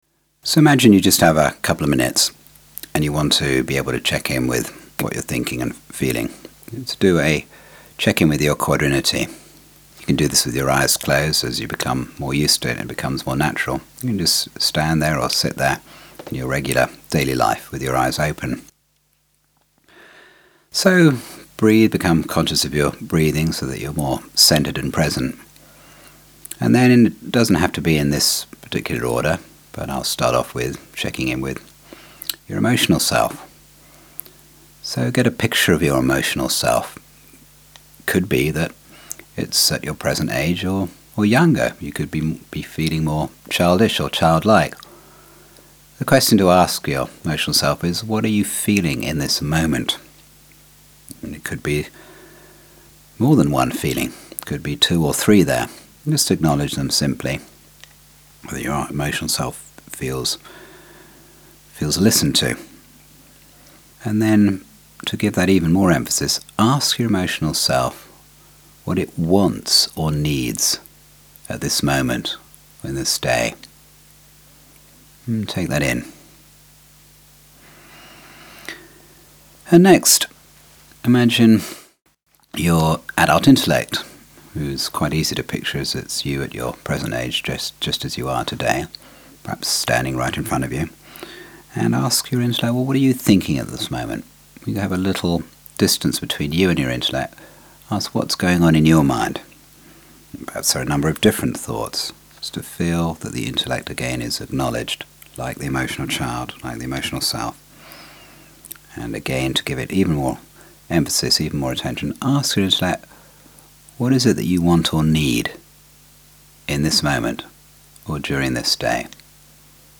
There are a few guided meditations on our website that we’ll be referring to on the workshop and that you’re welcome to access at any time.